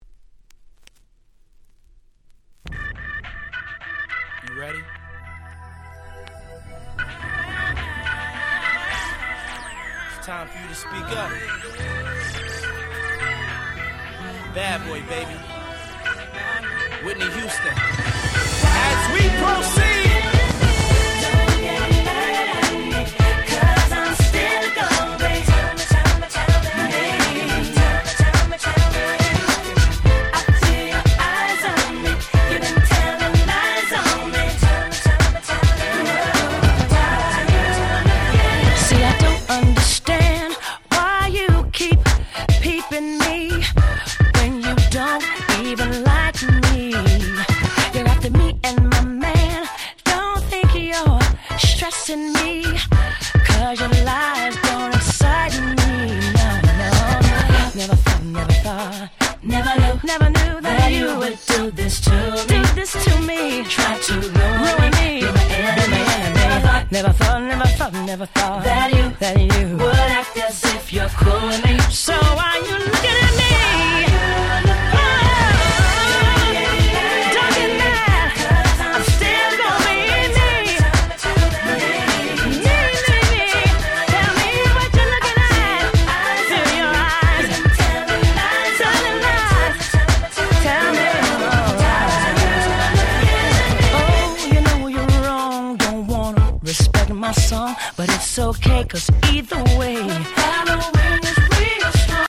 02' Smash Hit R&B !!
キャッチー系